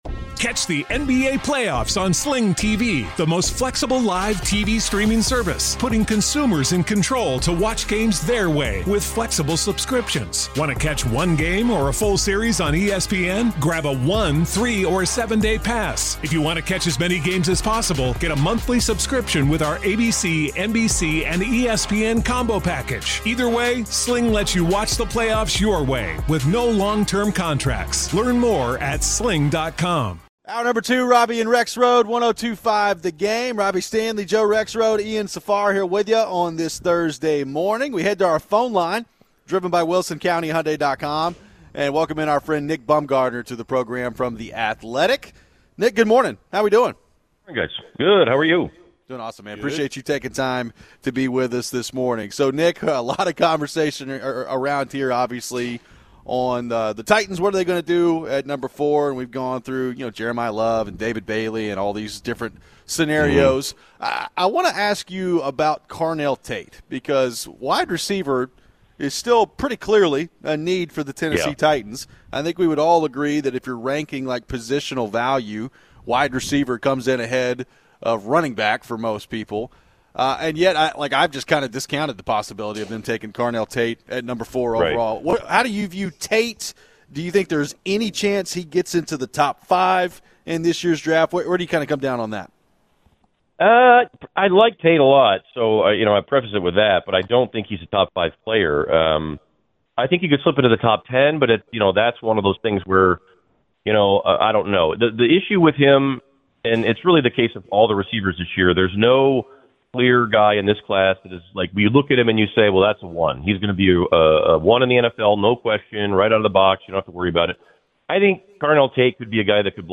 Could Dallas try to move up in the draft? We take your phones.